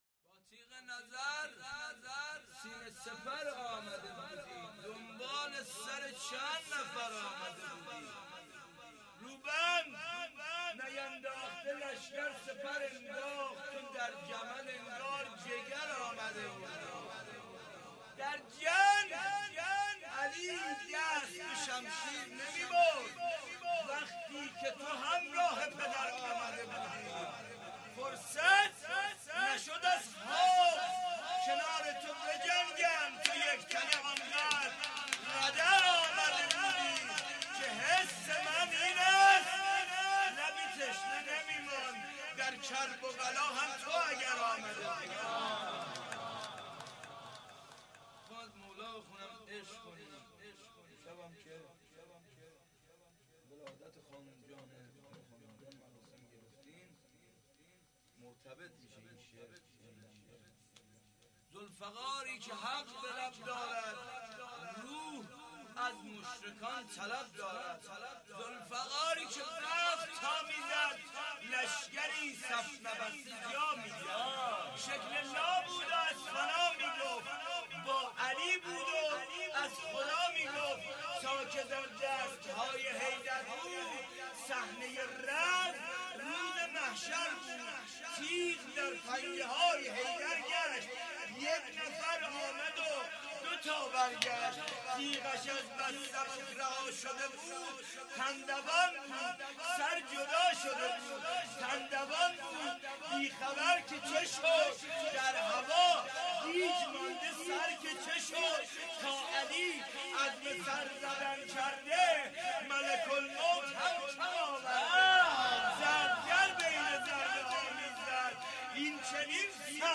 هیئت زواراباالمهدی(ع) بابلسر - رجز خوانی
جشن ولادت حضرت زهرا(س)